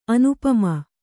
♪ anupama